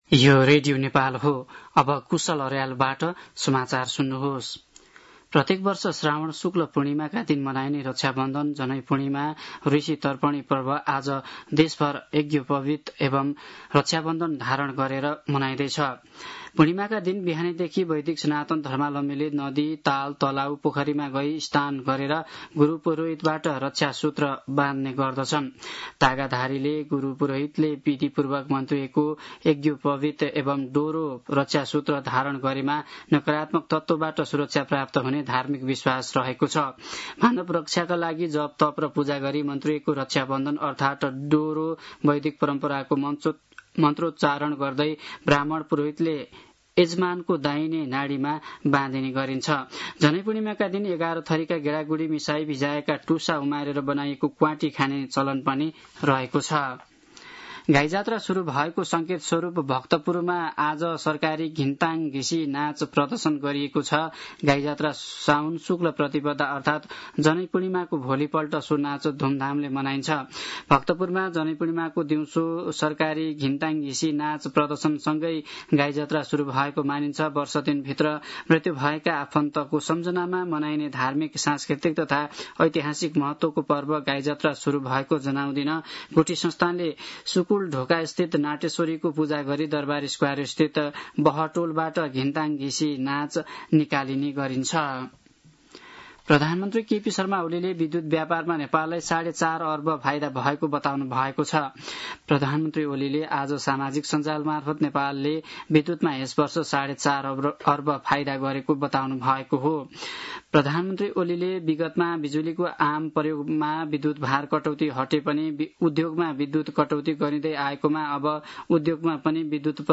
साँझ ५ बजेको नेपाली समाचार : २४ साउन , २०८२